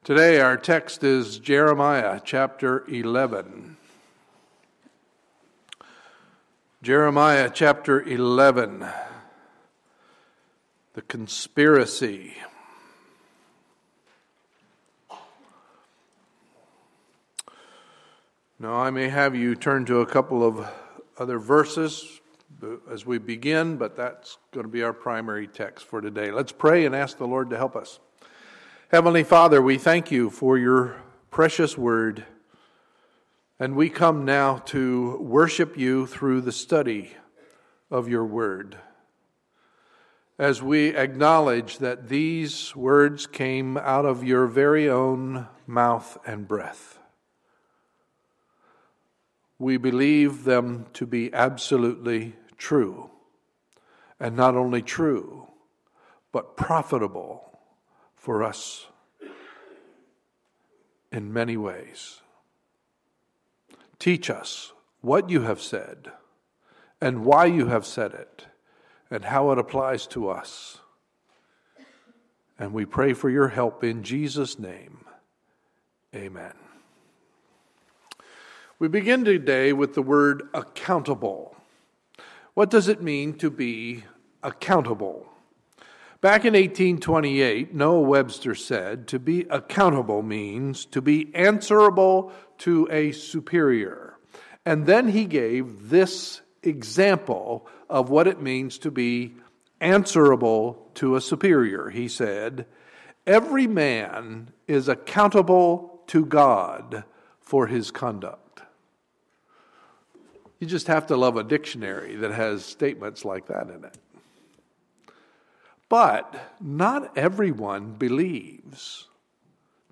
Sunday, March 29, 2015 – Sunday Morning Service